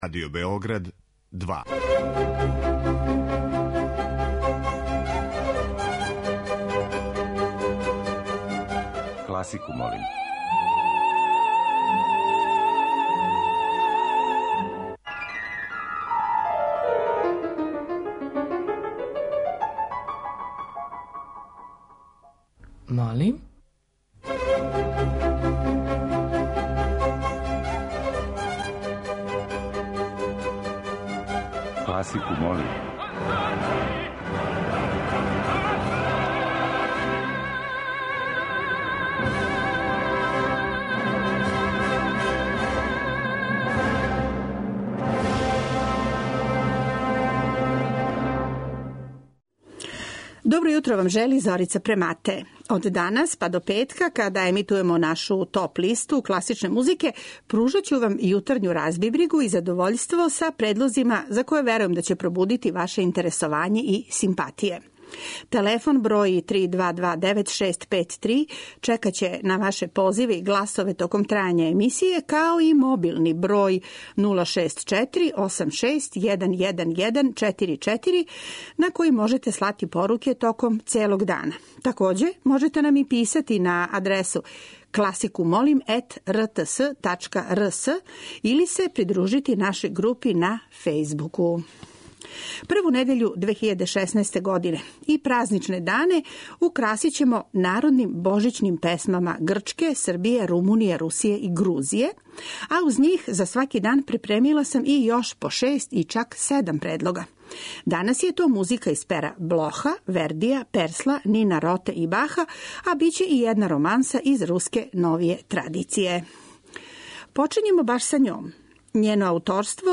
народну духовну песму, а из традиција руске, грчке, румунске и јерменске народне православне музике.
Уз њих, моћи ћете да чујете и хитове класичне музике, али и мање позната дела из пера композитора свих епоха, од анонимних аутора ренесансе, па до 21. века и најновијих остварења српских композитора.